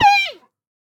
Minecraft Version Minecraft Version snapshot Latest Release | Latest Snapshot snapshot / assets / minecraft / sounds / mob / panda / hurt1.ogg Compare With Compare With Latest Release | Latest Snapshot